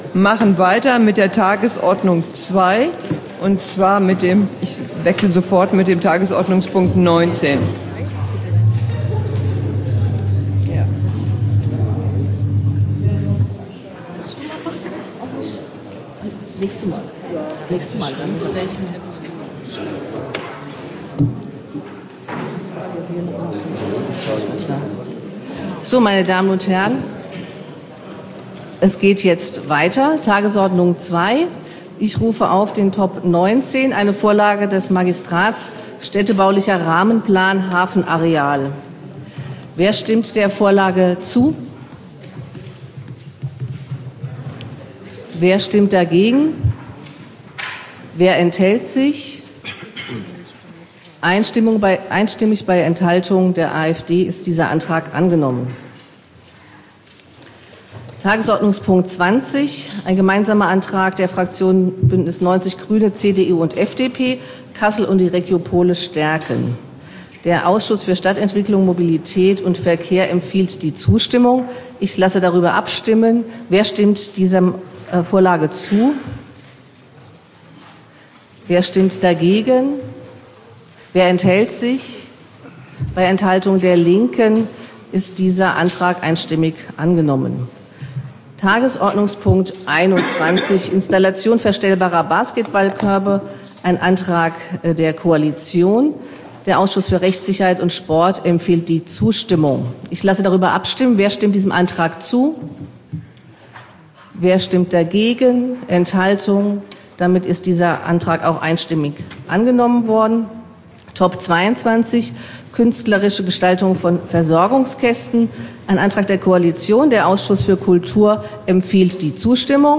Tonaufzeichnung Tagesordnungspunkte 19 bis 23 (exportiert: 16.09.2024)